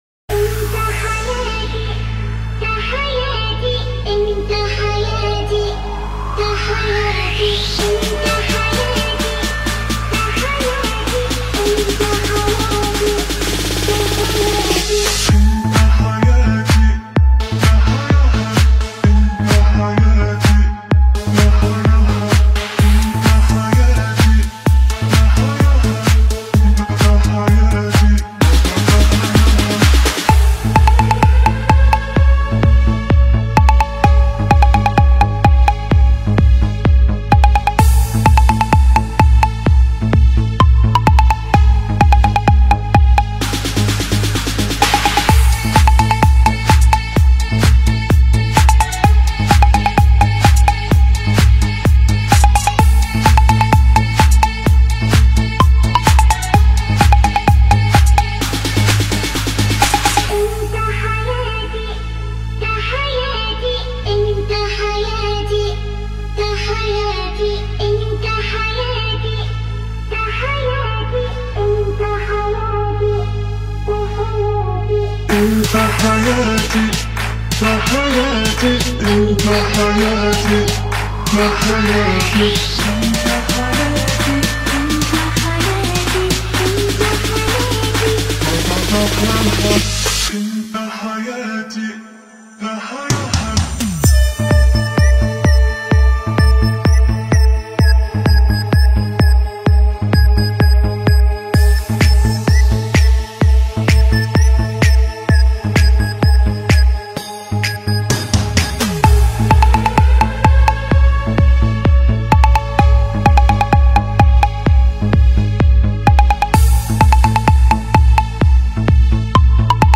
Arabic Remix